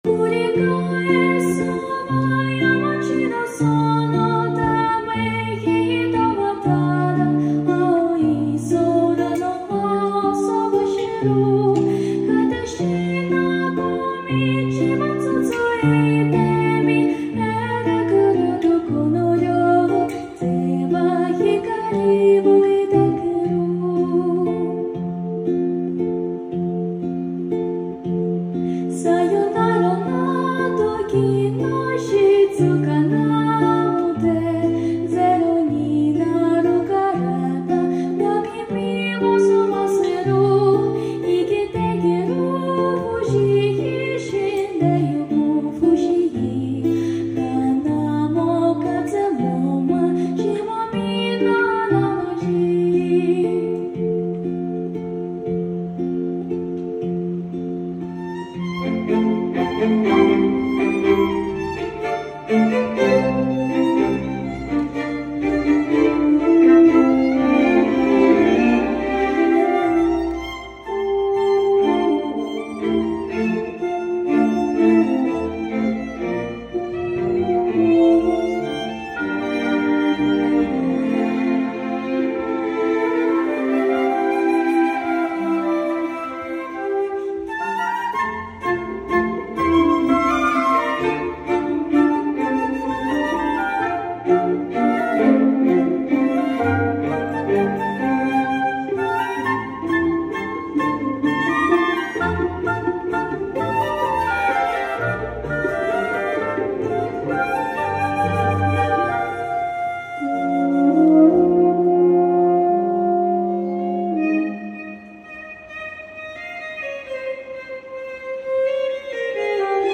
аніме концерт